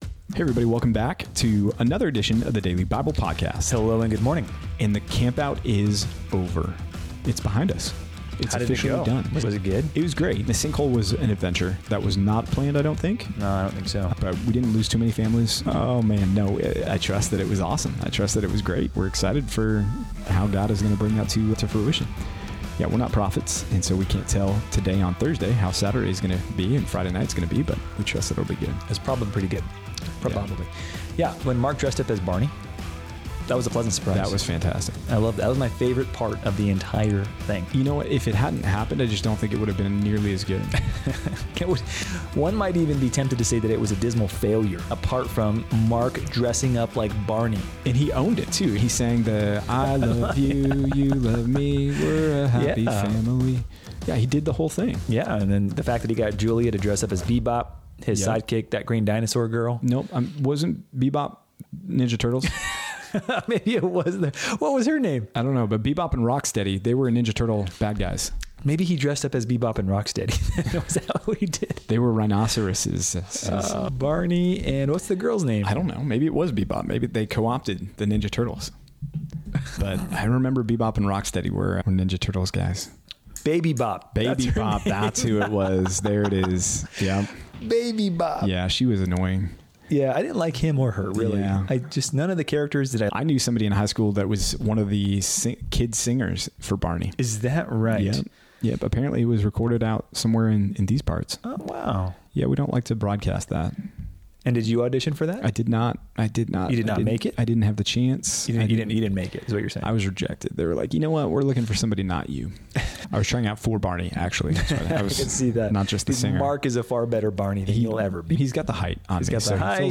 In this episode of the Daily Bible Podcast, the hosts reflect on recent events including a camp-out and delve deep into theological discussions about Satan's access to heaven, drawing from the Book of Job and Revelation. They explore God's sovereignty, especially in the context of King Saul and David, discussing passages from 1 Samuel 15-17.